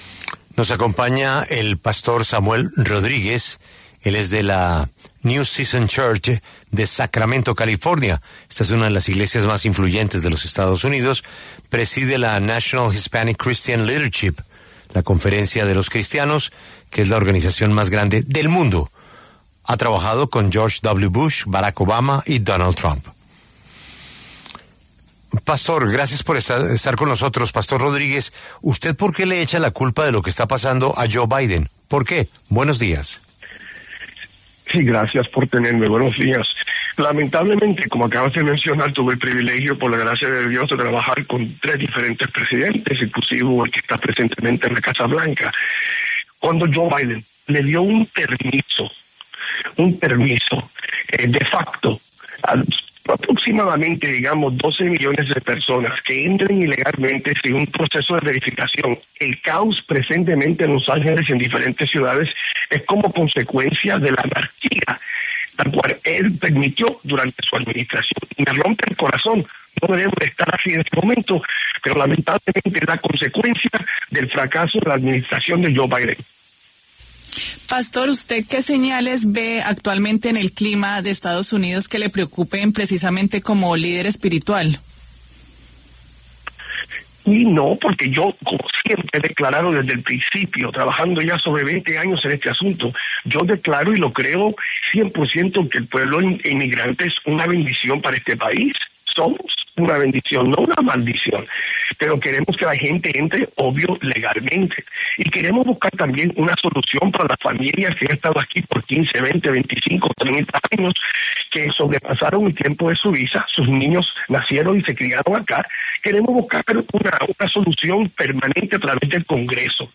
Samuel Rodríguez, pastor principal de New Season Church en Sacramento, California, habló en La W sobre el toque de queda y los arrestos masivos en Los Ángeles tras las protestas contra las redadas migratorias.